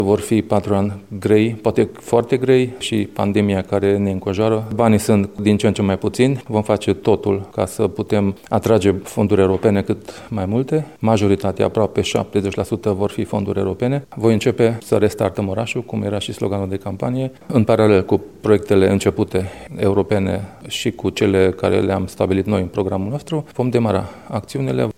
La depunerea jurământului, edilul a anunțat că prioritatea sa este atragerea de fonduri europene pentru dezvoltarea orașului.